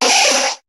Cri de Nidoran♀ dans Pokémon HOME.